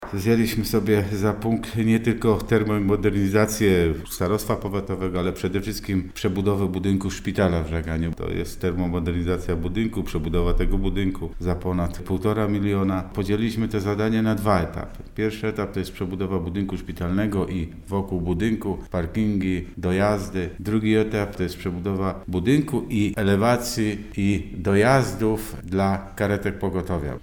’- Te najważniejsze budynki, które musimy docieplić to szpital, starostwo i liceum – mówi Henryk Janowicz, starosta żagański.